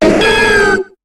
Cri de Méganium dans Pokémon HOME.